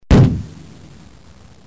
جلوه های صوتی
دانلود صدای بمب و موشک 26 از ساعد نیوز با لینک مستقیم و کیفیت بالا